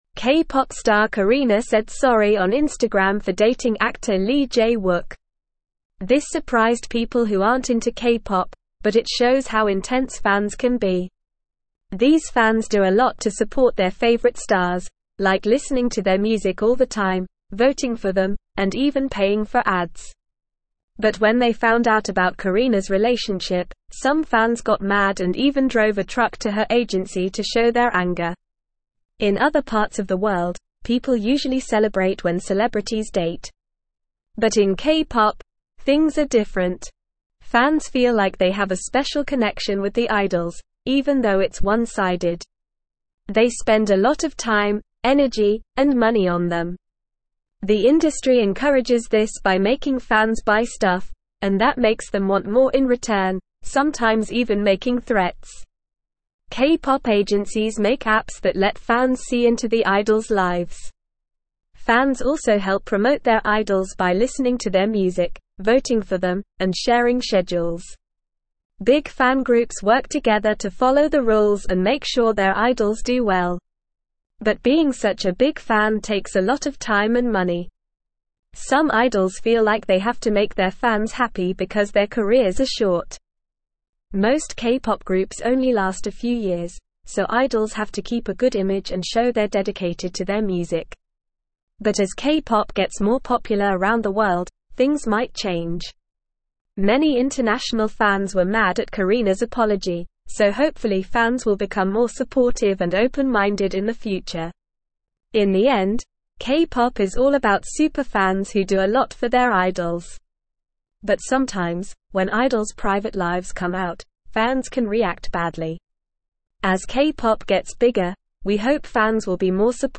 English-Newsroom-Upper-Intermediate-NORMAL-Reading-K-pop-Fans-Entitlement-and-the-Industrys-Evolution.mp3